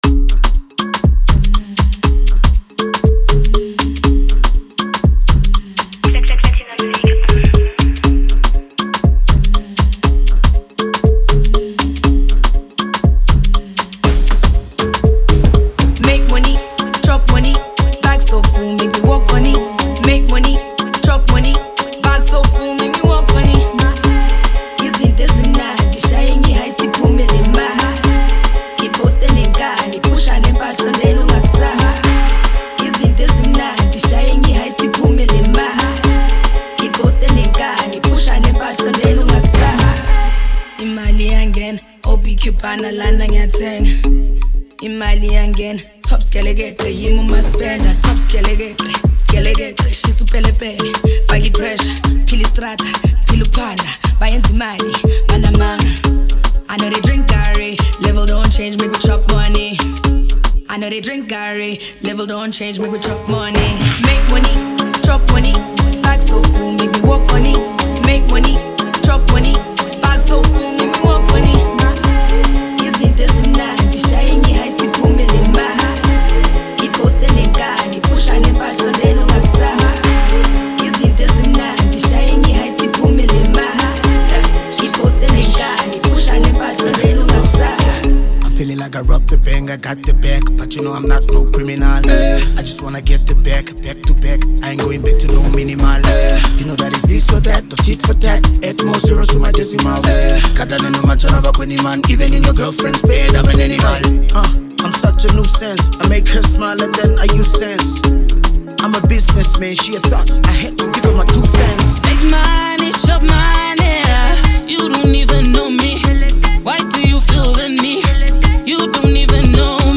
a talented South African rapper
vibrant hip-hop track